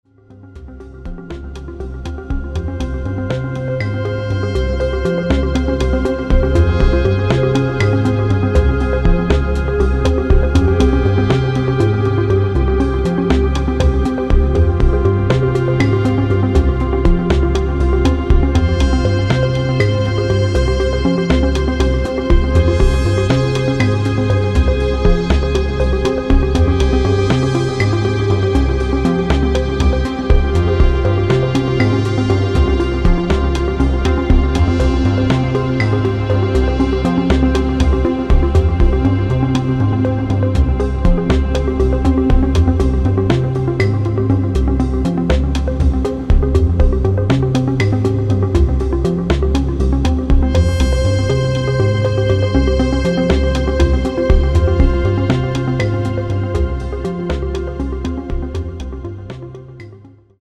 One Long Album Length Track https